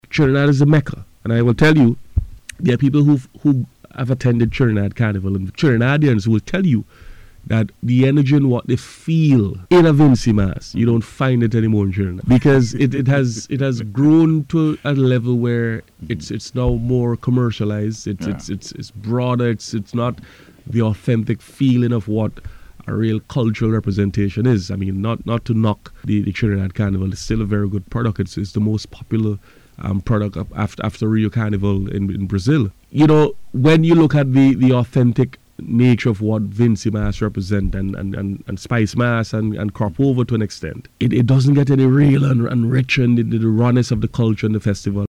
Speaking on the Face to Face programme on NBC Radio on Wednesday, Minister of Tourism and Culture Carlos James commended the production of events hosted by the CDC for Vincy Mas 2025.